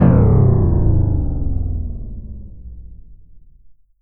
SCIFI_Down_02_mono.wav